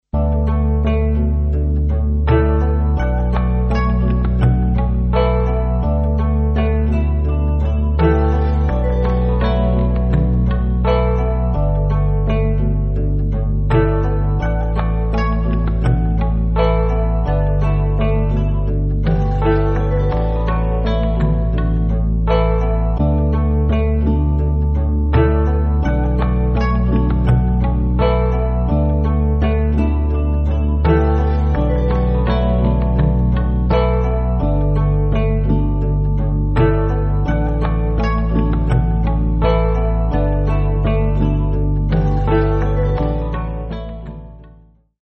Guitar
relaxed slow instr.